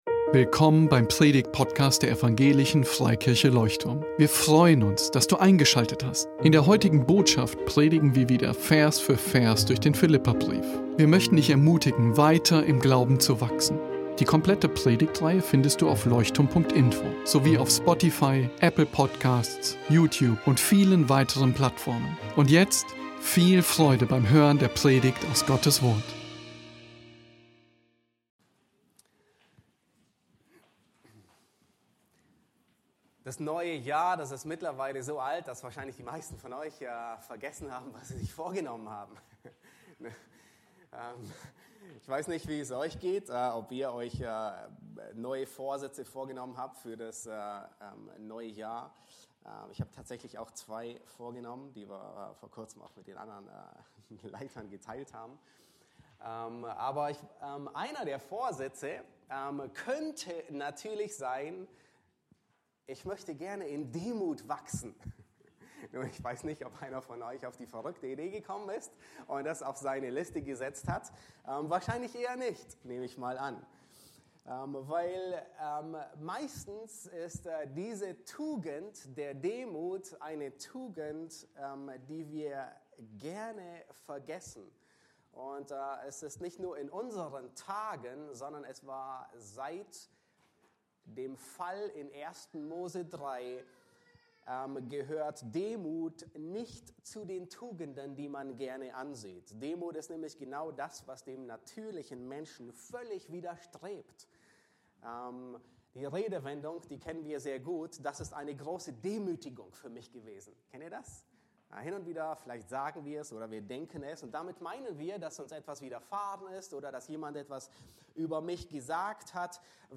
Philipper 2,5–11 Predigtgliederung 1) Das Vorbild für Demut (V. 5-6) a) Demut beginnt in der Gesinnung!